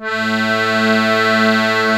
A2 ACCORDI-L.wav